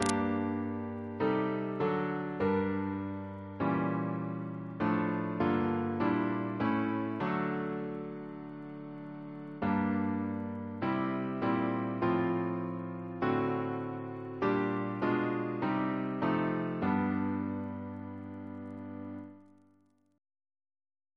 Double chant in G minor Composer